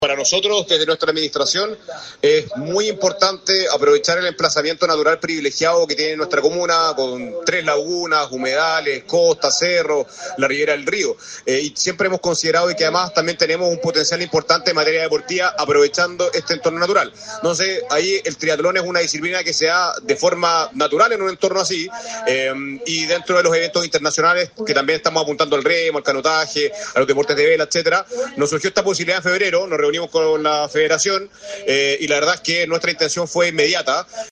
Juan Pablo Spoerer, alcalde de San Pedro de la Paz, detalló el proceso que permitió contar con esta prueba y relevó el emplazamiento natural privilegiado de la comuna para albergar este tipo de competencias.